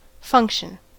function: Wikimedia Commons US English Pronunciations
En-us-function.WAV